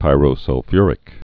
(pīrō-sŭl-fyrĭk)